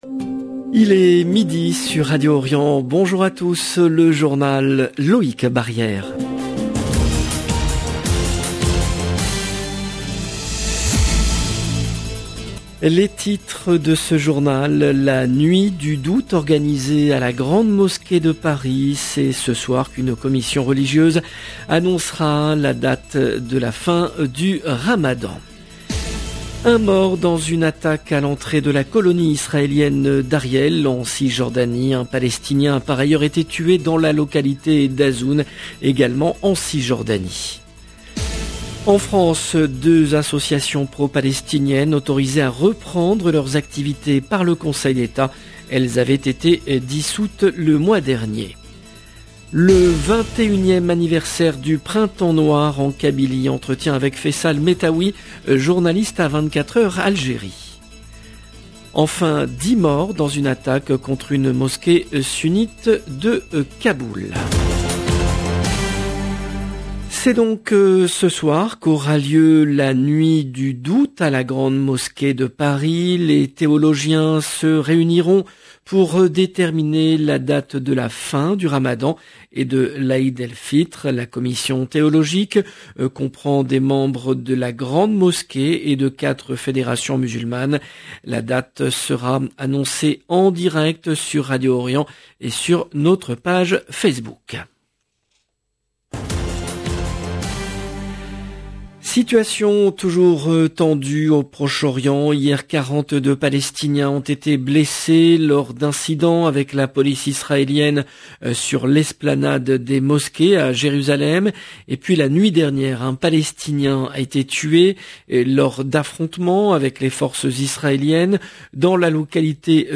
Journal présenté par